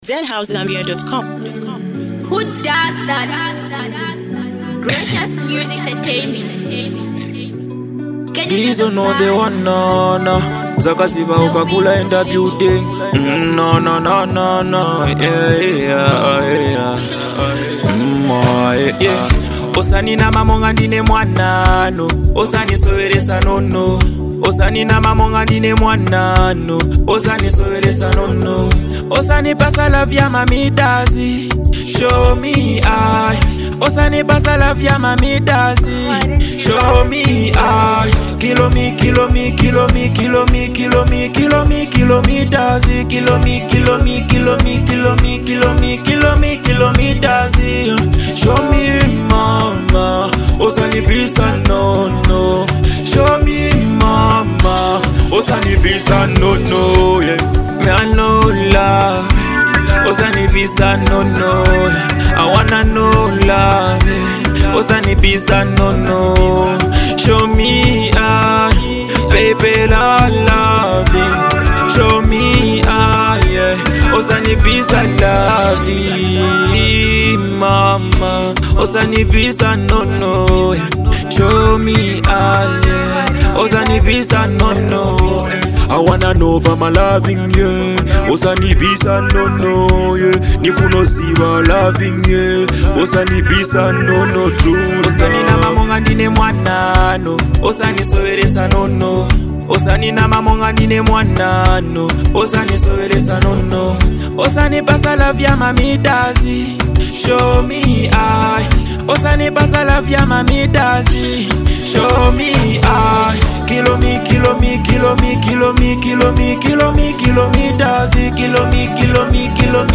motivational anthem